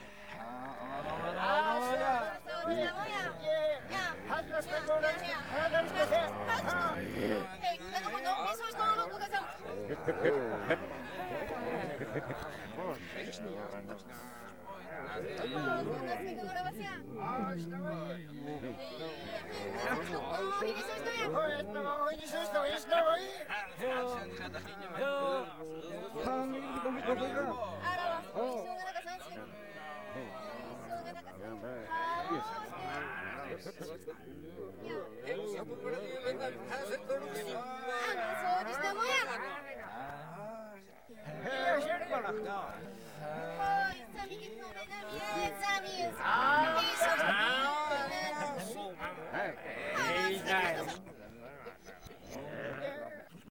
环境音
0022_循环音_酒吧.ogg